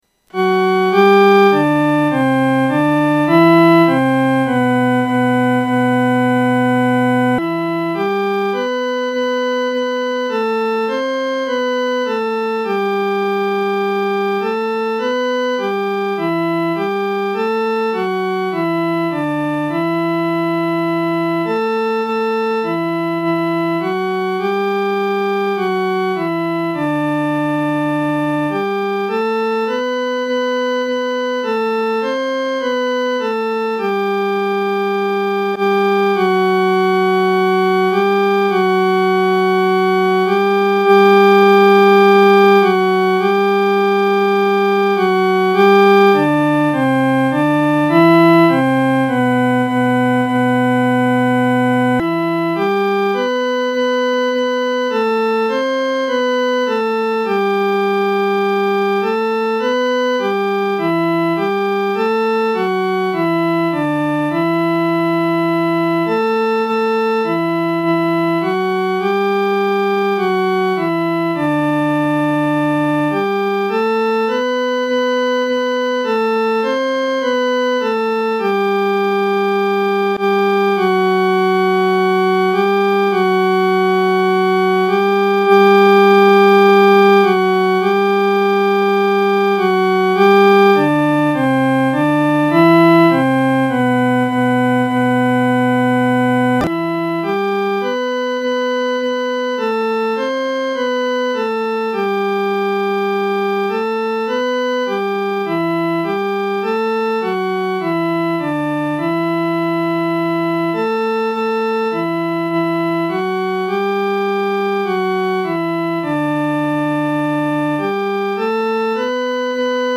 伴奏
女低
本首圣诗由网上圣诗班（环球）录音
Latin hymn, 12th Century